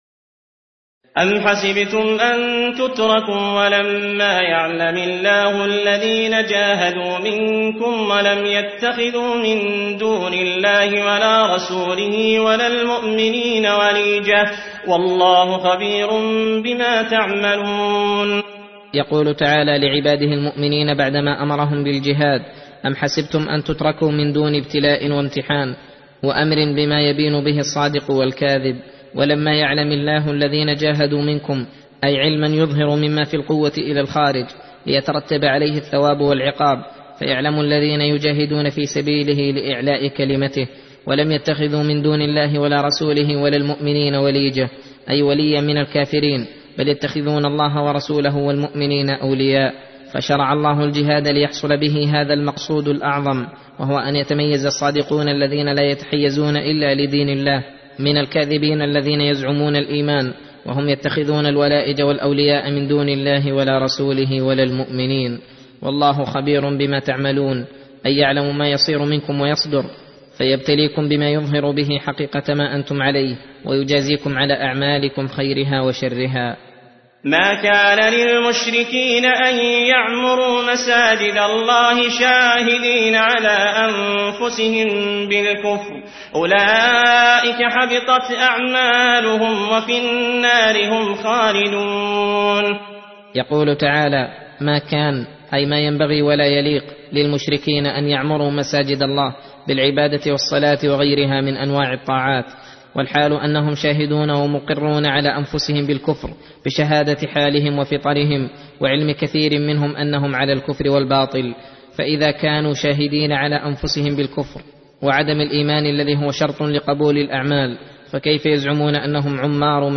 درس (17) : تفسير سورة التوبة (16-28)